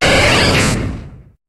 Cri de Pyroli dans Pokémon HOME.